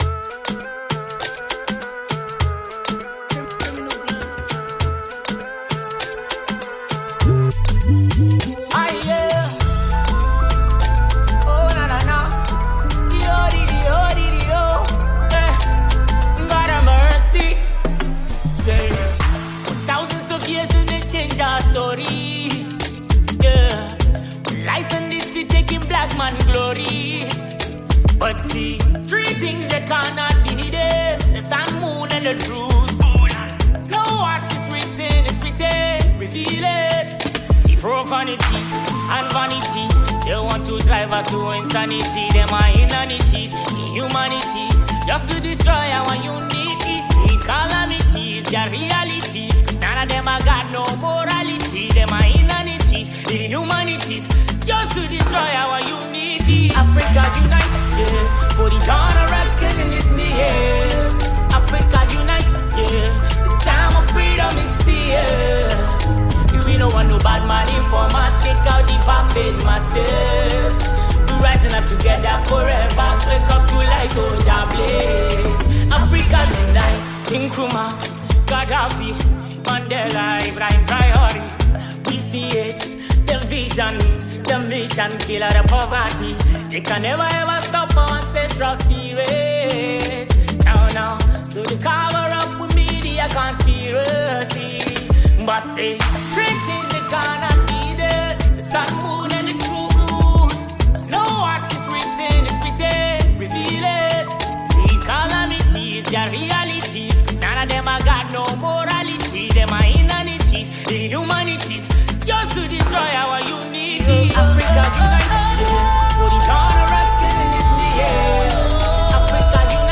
Ghanaian Afrobeat singer-songwriter